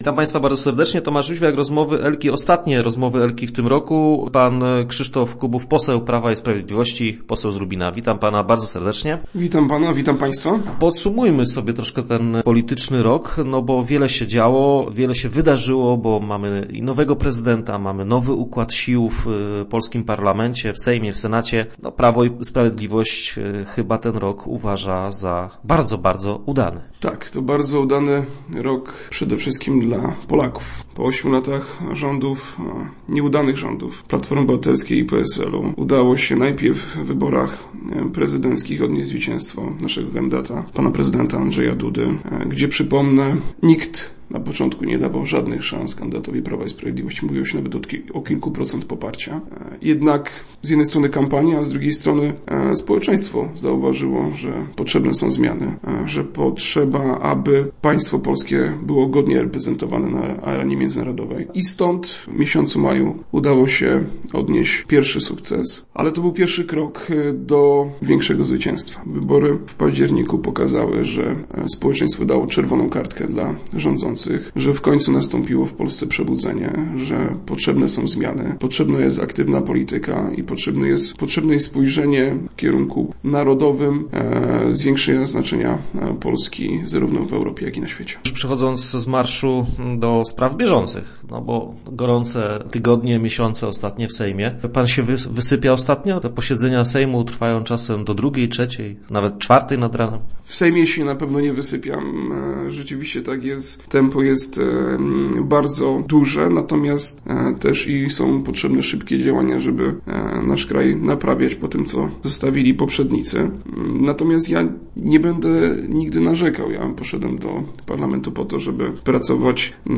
Naszym go�ciem by� pose� PiS, Krzysztof Kubów.